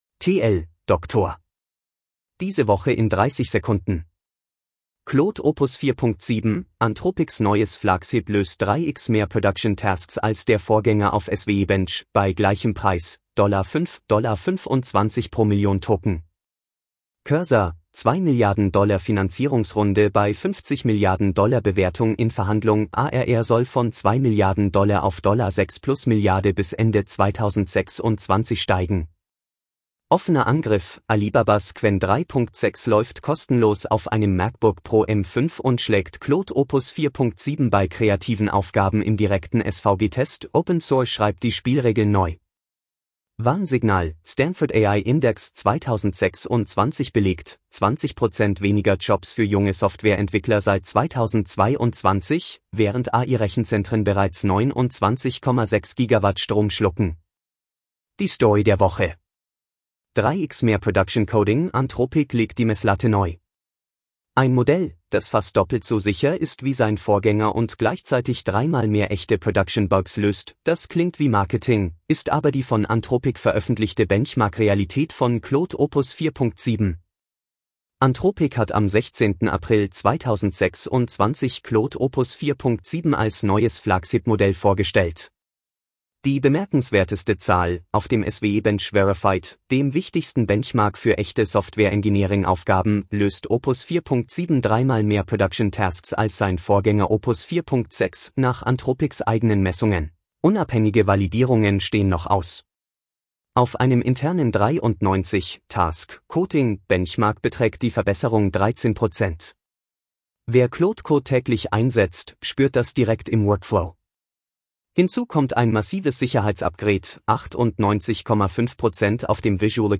Vorgelesen mit edge-tts (de-DE-ConradNeural)
Enthält PerthNet-Audio-Watermark.